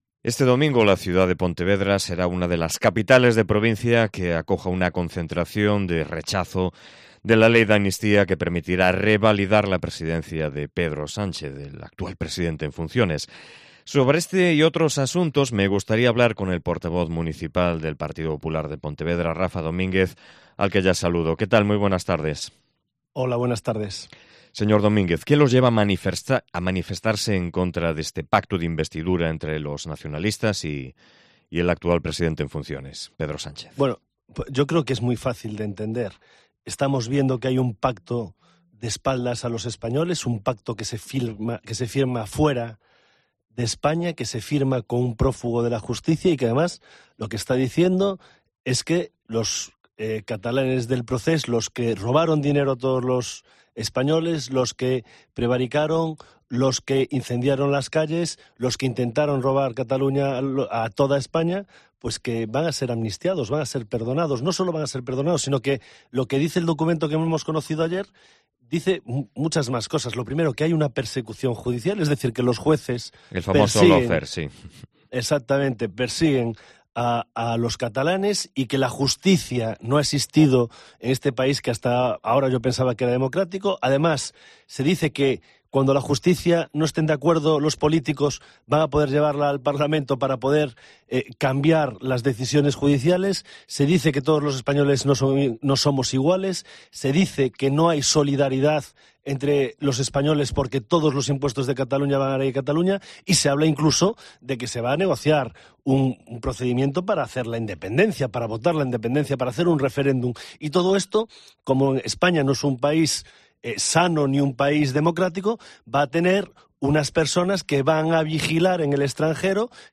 AUDIO: Entrevista patrocinada por el Grupo Municipal del Partido Popular